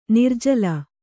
♪ nirjala